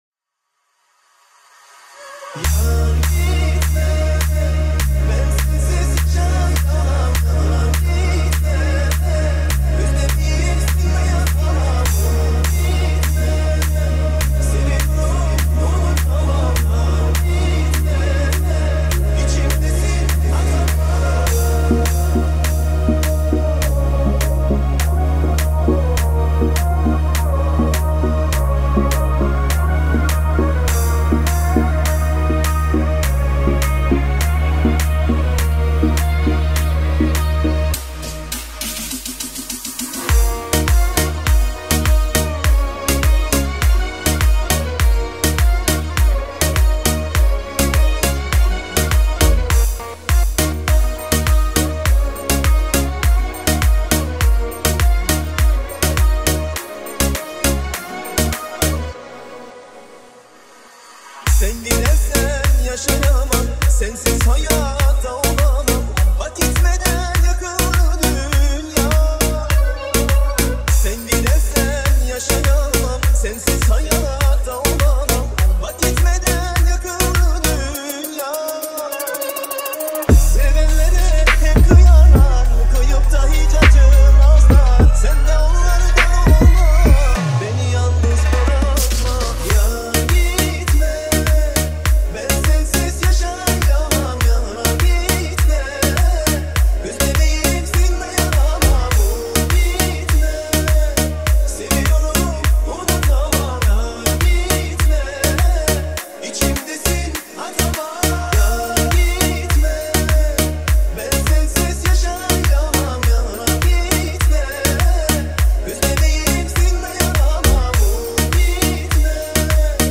ریمیکس شاد ترکیه ای